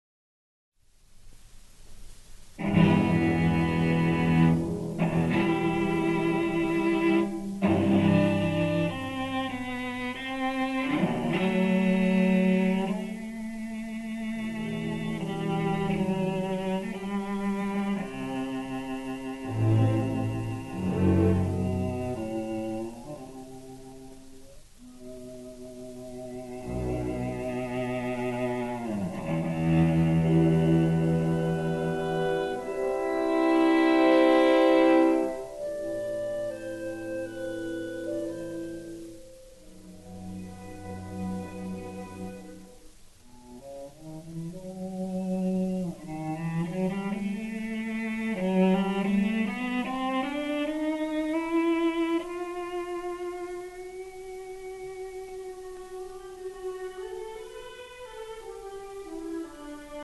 Edward William Elgar - Cello Concerto in E minor - Op 85 - I - Adagio - Moderato
Edward William Elgar - Cello Concerto in E minor - Op 85 - I - Adagio - Moderato - Great Classical Music